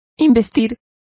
Complete with pronunciation of the translation of inaugurate.